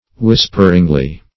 Search Result for " whisperingly" : The Collaborative International Dictionary of English v.0.48: Whisperingly \Whis"per*ing*ly\, adv.